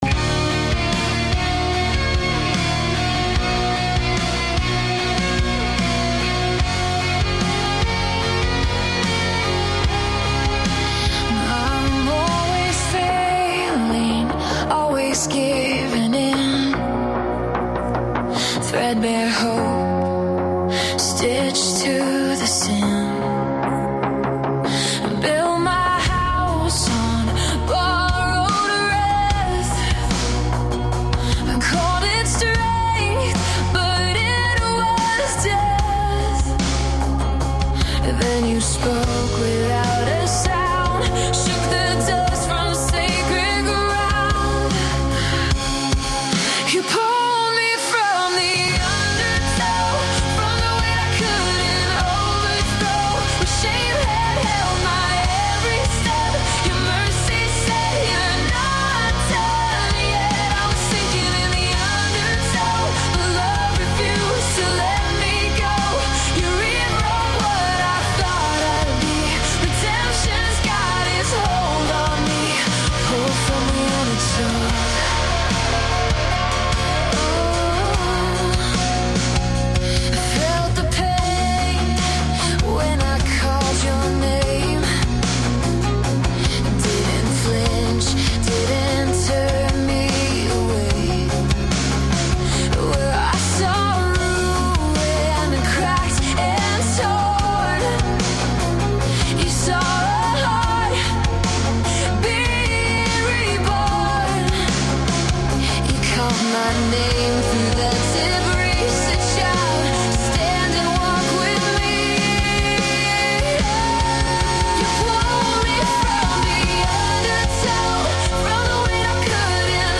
Christian